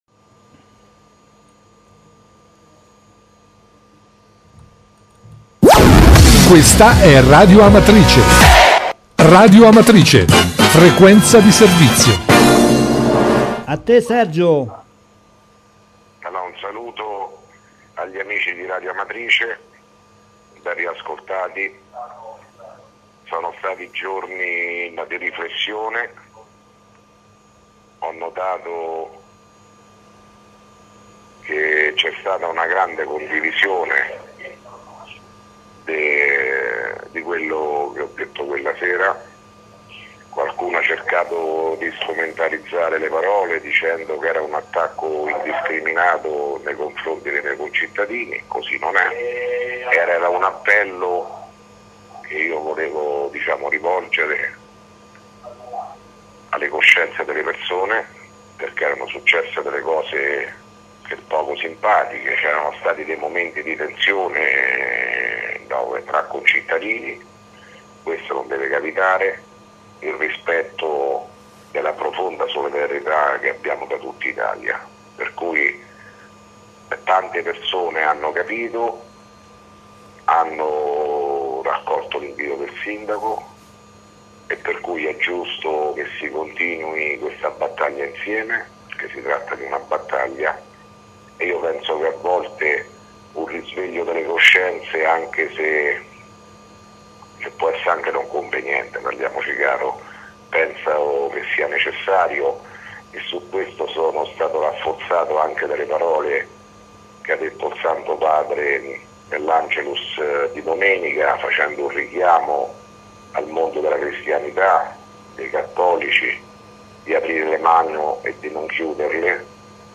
Di seguito il messaggio audio del Sindaco Sergio Pirozzi, del 1 febbraio 2017.